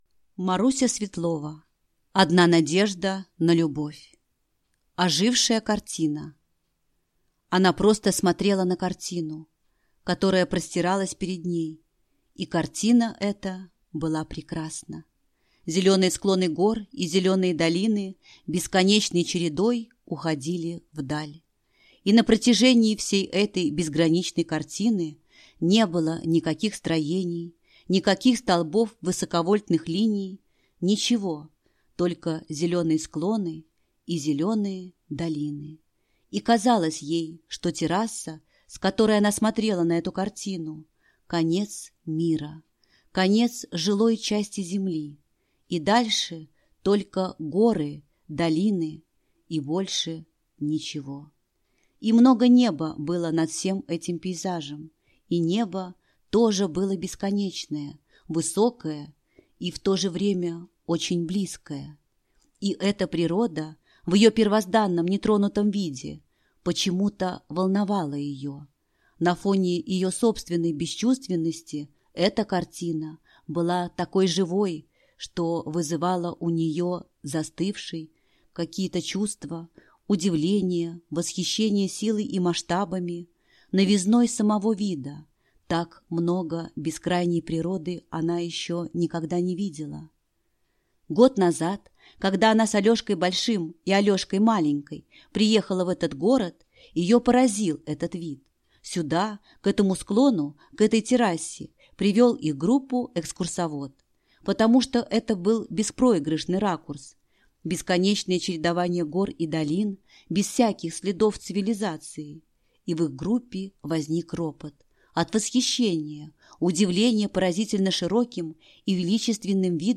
Аудиокнига Одна надежда на любовь (сборник) | Библиотека аудиокниг